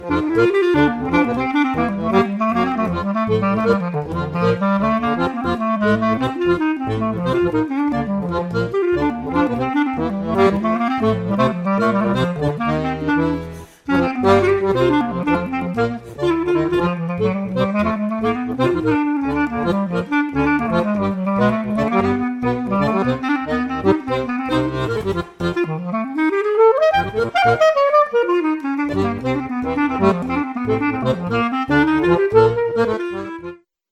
clarinette
accordéon
guitare, chant
contrebasse